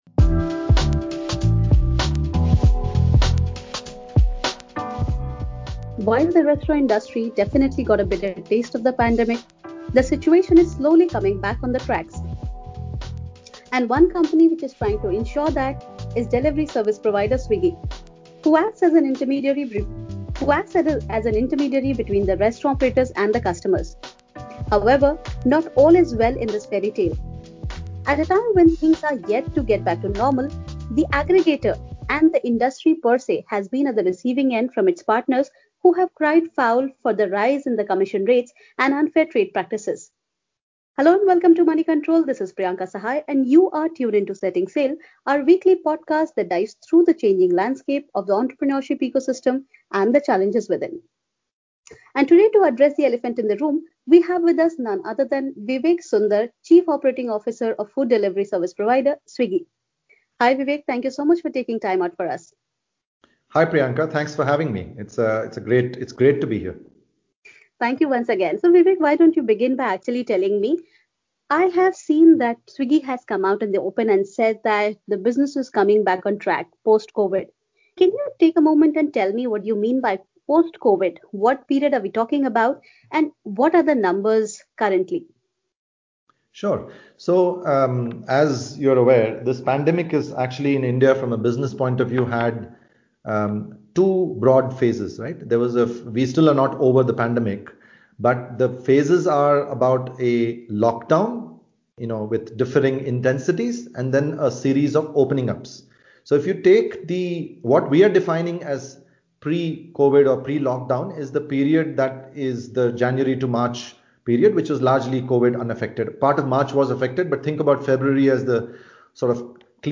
a free-wheeling chat